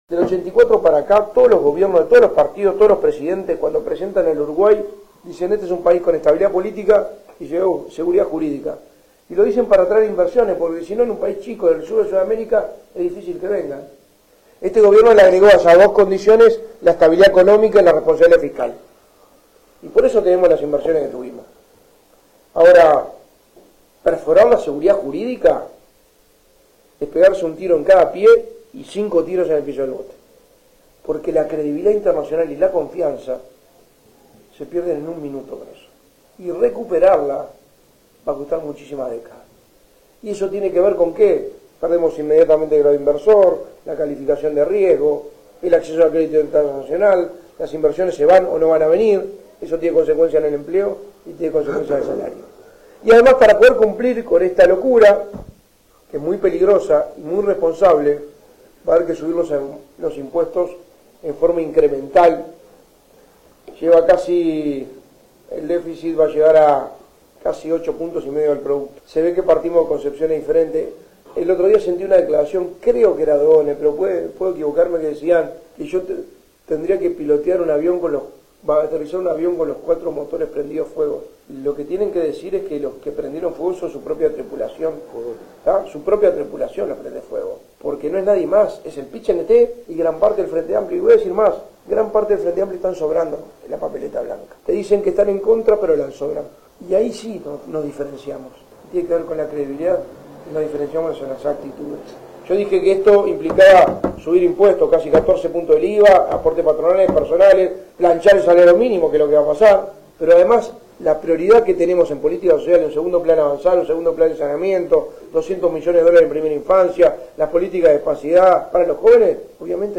El candidato a la Presidencia por el PN, Álvaro Delgado, realizó este jueves una visita a San José de Mayo, brindando una rueda de prensa en el Hotel Centro, antes de mantener una reunión con la dirigencia política departamental, en la Casa del Partido.